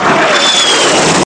artillery_projectile_2.wav